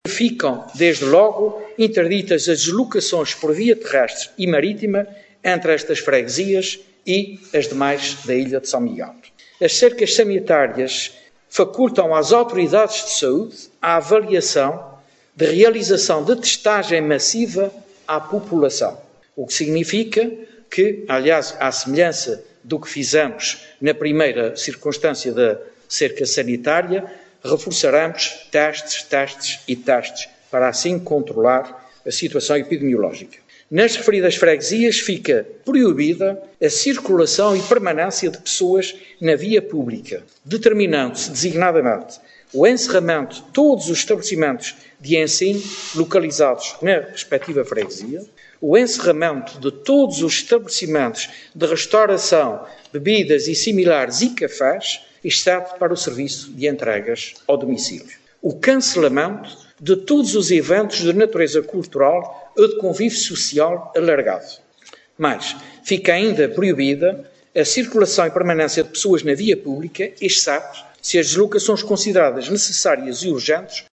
O anúncio foi feito, esta tarde, pelo presidente do Governo Regional dos Açores, José Manuel Bolieiro, numa conferência de imprensa, em Ponta Delgada, para divulgação de novas medidas de combate à pandemia, aprovadas em Conselho de Governo.